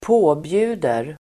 Uttal: [²p'å:bju:der]